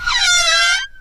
CookerClose.ogg